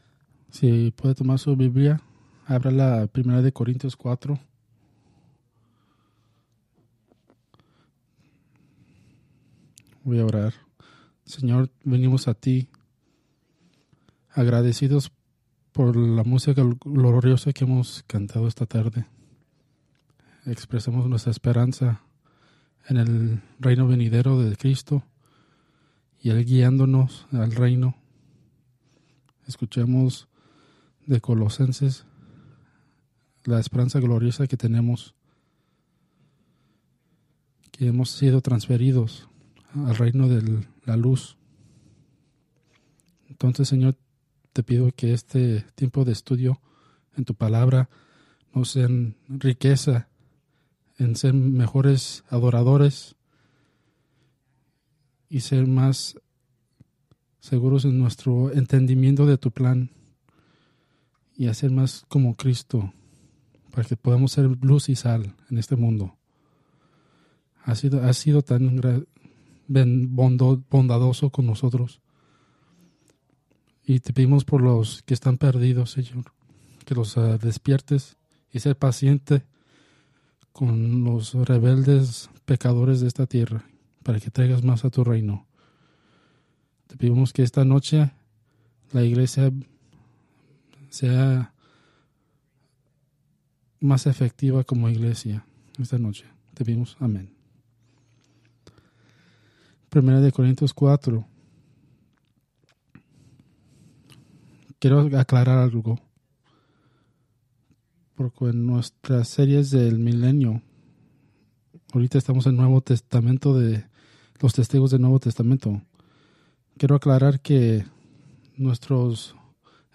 Preached November 24, 2024 from Escrituras seleccionadas